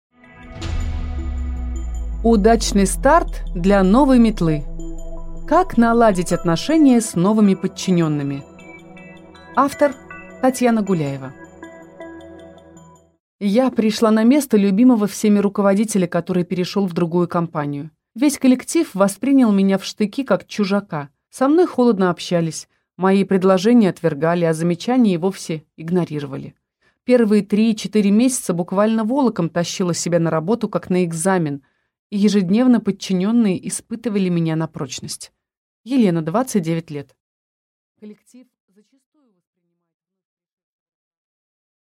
Аудиокнига Удачный старт для «новой метлы» | Библиотека аудиокниг